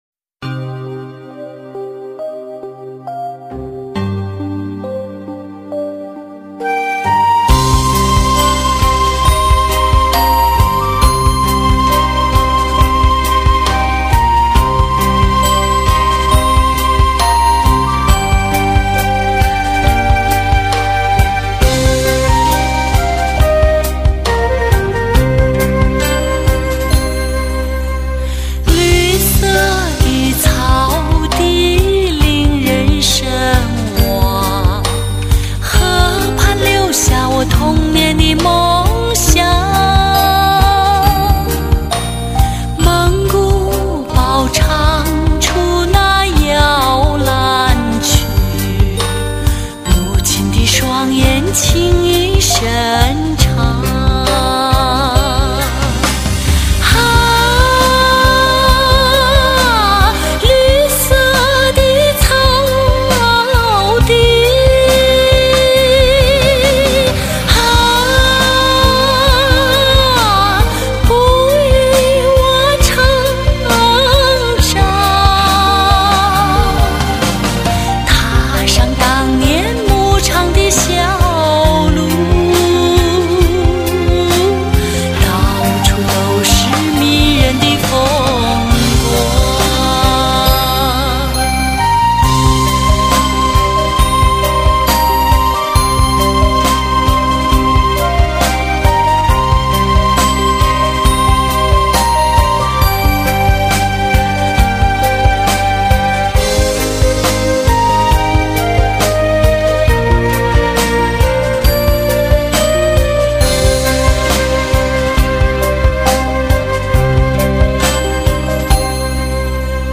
风格独特 大气天成 是蒙古民族曲风创作歌曲中的珍品